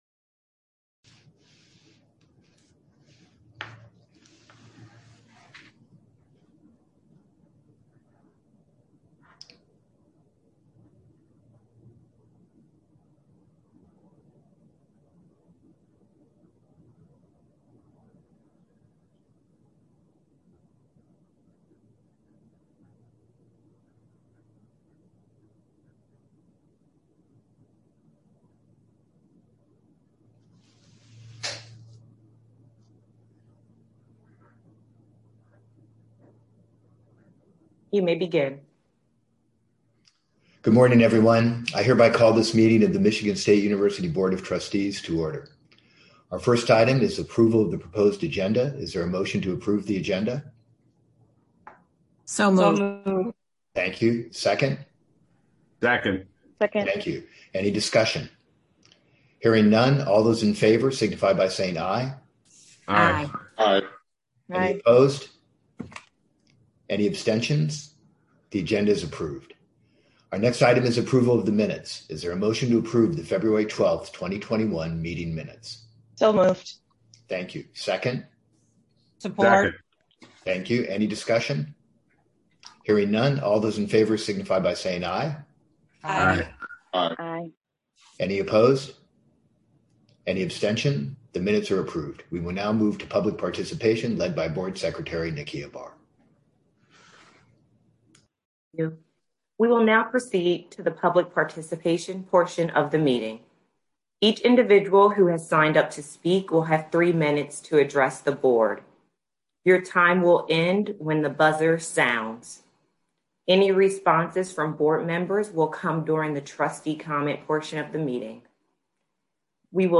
Where: Via Zoom